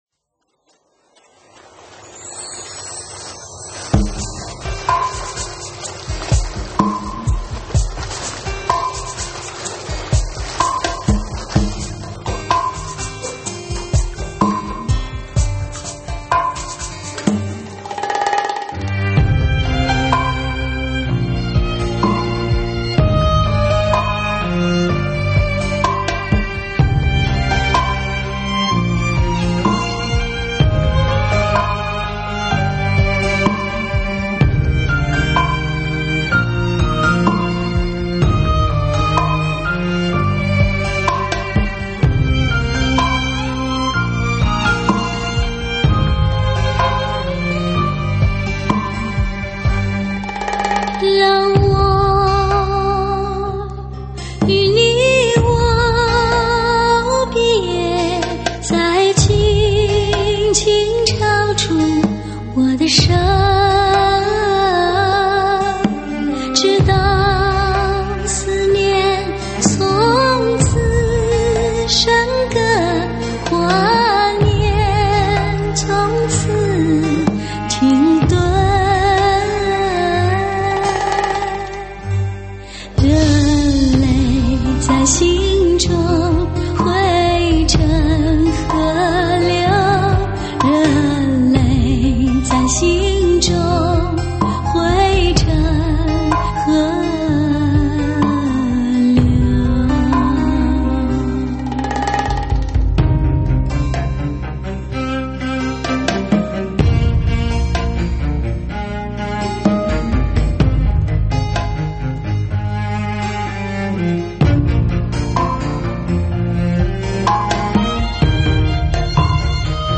汽车音响极品专用HI-FI
这声音仿佛是在轮回的时空发出来的，空旷而遥远，在